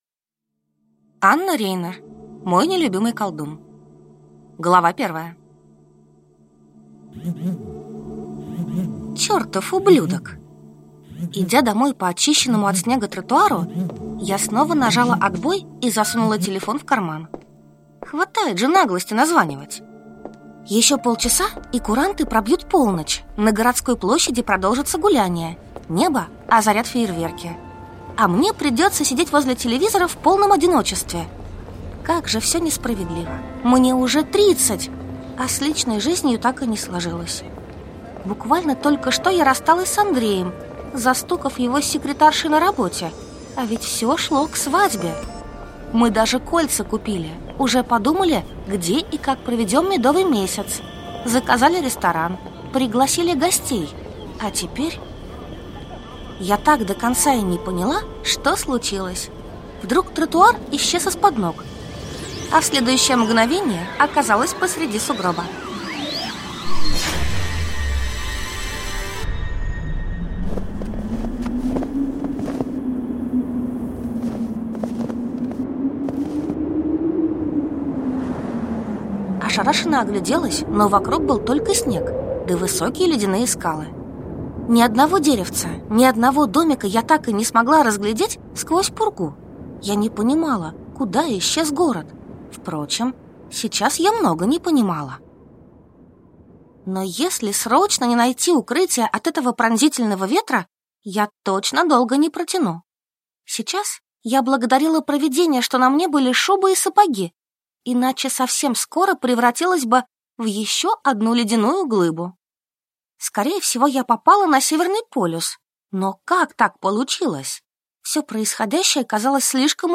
Аудиокнига Мой (не) любимый колдун | Библиотека аудиокниг